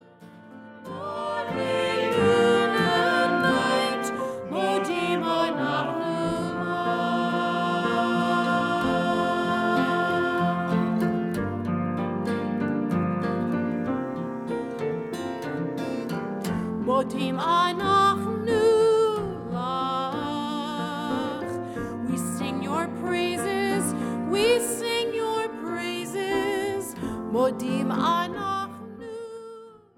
adult choir, instrumental ensemble